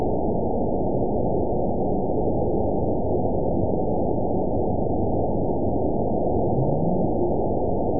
event 920517 date 03/28/24 time 16:46:17 GMT (1 month ago) score 9.59 location TSS-AB03 detected by nrw target species NRW annotations +NRW Spectrogram: Frequency (kHz) vs. Time (s) audio not available .wav